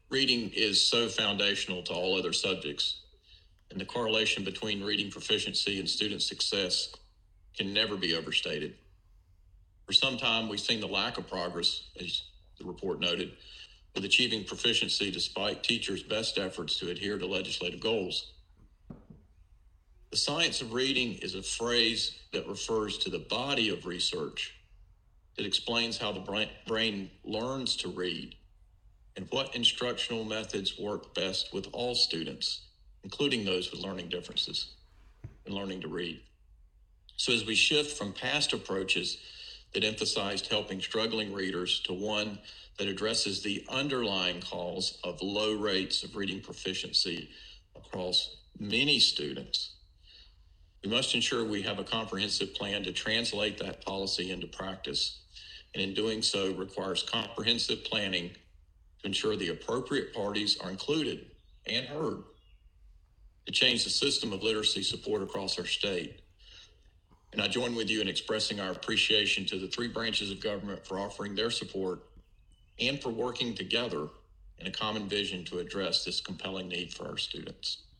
Eric Davis on literacy at the April 2021 State Board meeting